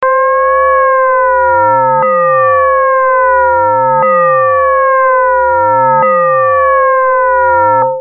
7.落ちる様なベルの音色
crfmfallingbell.mp3